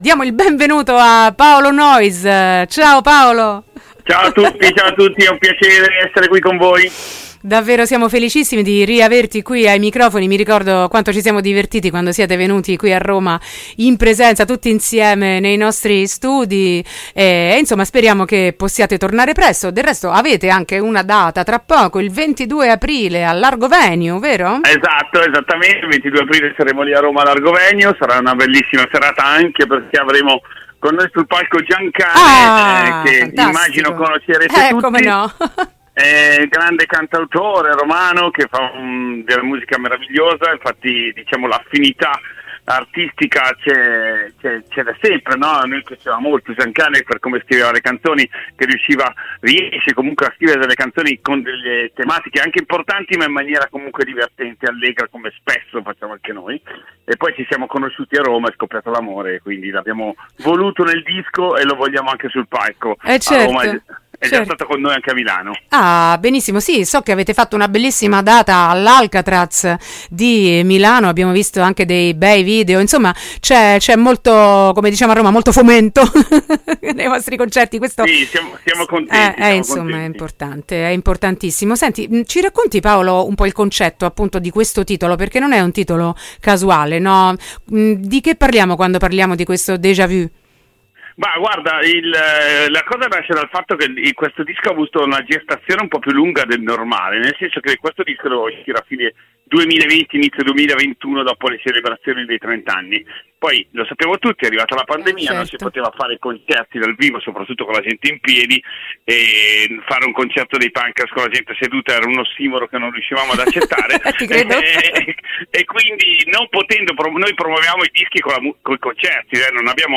Stiamo vivendo un “Electric Déjà-vu”? Intervista ai Punkreas | Radio Città Aperta
intervista-punkreas.mp3